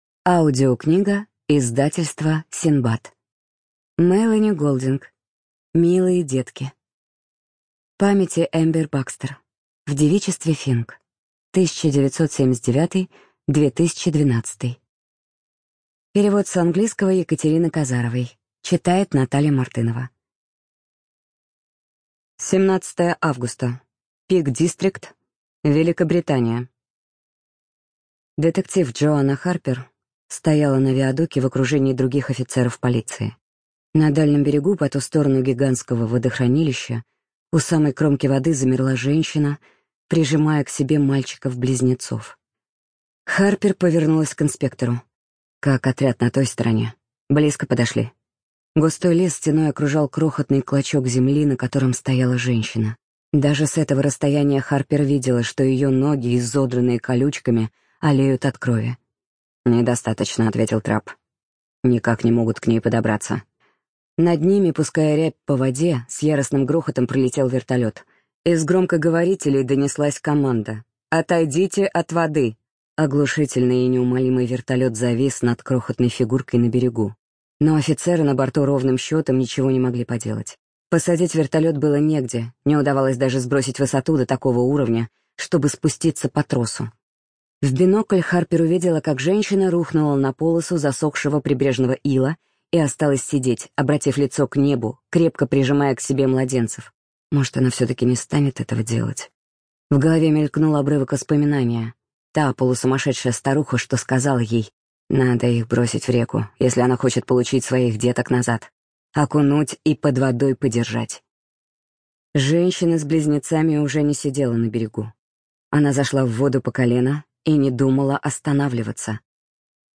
Студия звукозаписиСиндбад